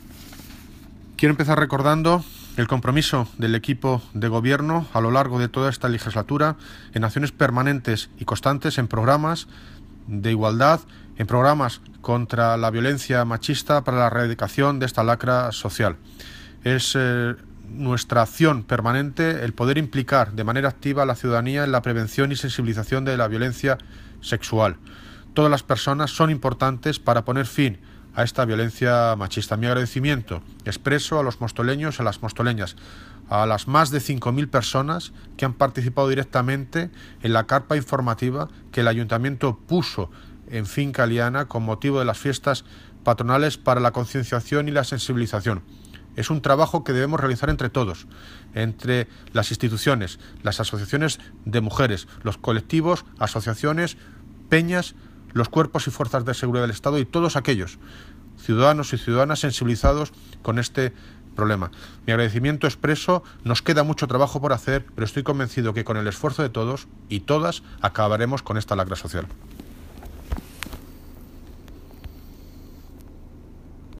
Audio - David Lucas (Alcalde de Móstoles) Sobre CAMPAÑA IGUALDAD FIESTAS PATRONALES